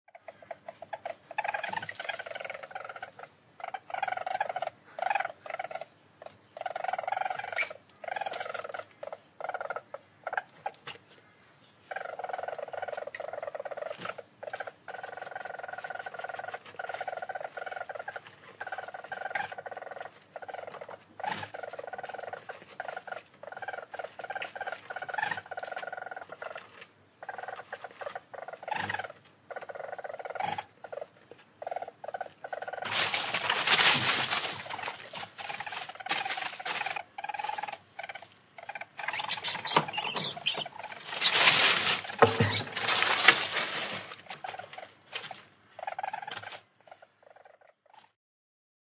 Звуки издаваемые ласой животным